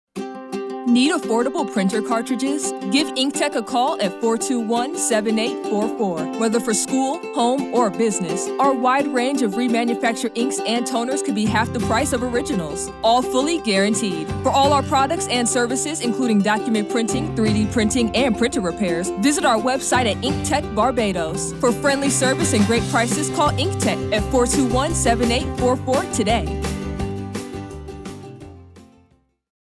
Below is an audio ad created for Inktech which was played on Starcom Network’s Life 97.5 FM:
inktech-radio-ad.mp3